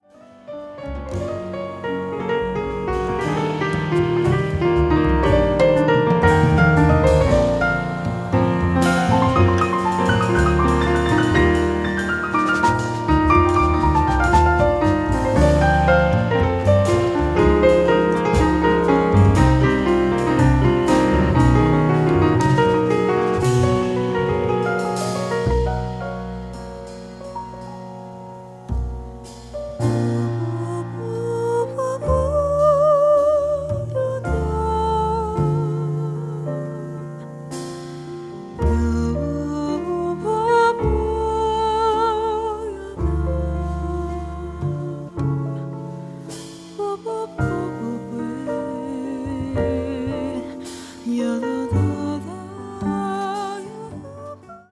voc
piano
bass
drums